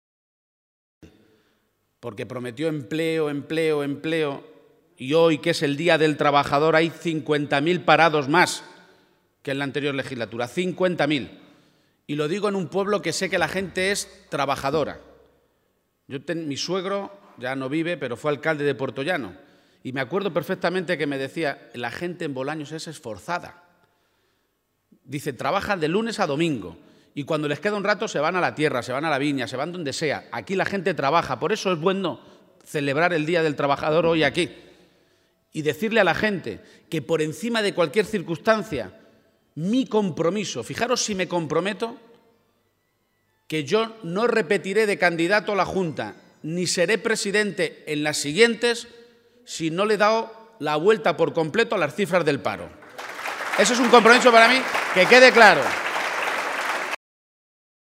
García-Page ha realizado estas declaraciones en Bolaños de Calatrava, un pueblo que ha definido como trabajador por antonomasia y donde también ha aprovechado para realizar otro anuncio importante.